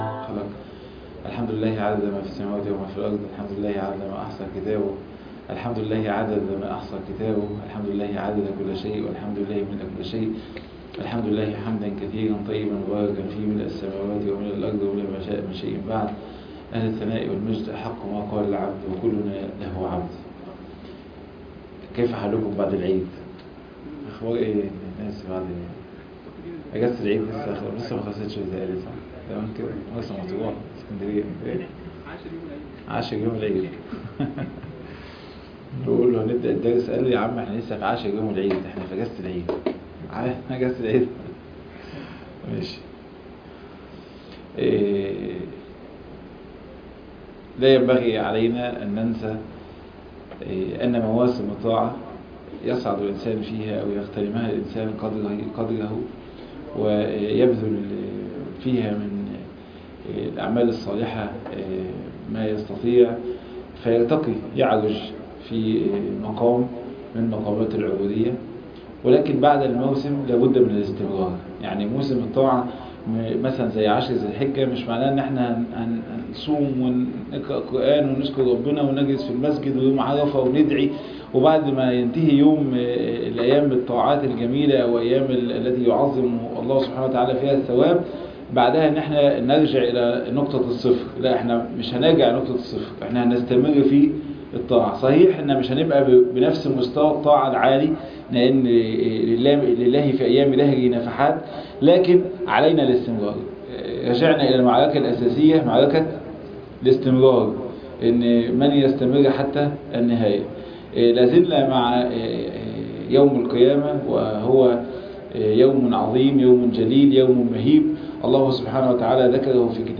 الدار الآخرة -الدرس الثالث أ -عمال الكافرين يوم القيامة